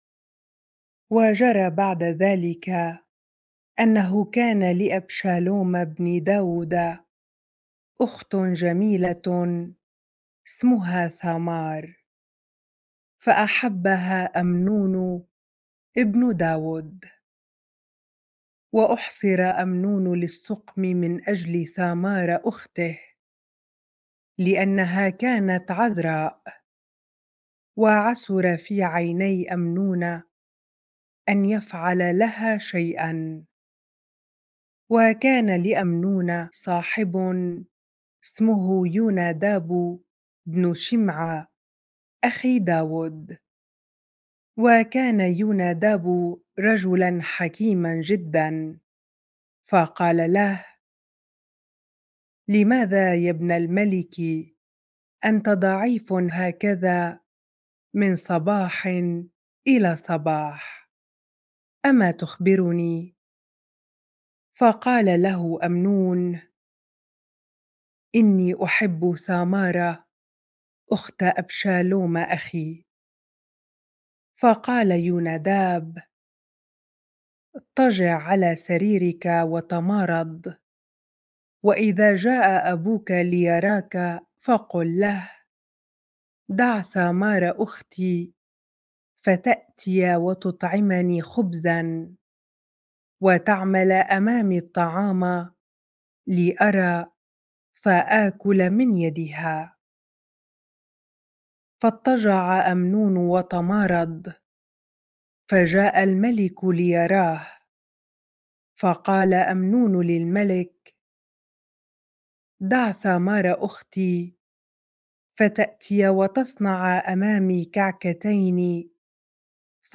bible-reading-2Samuel 13 ar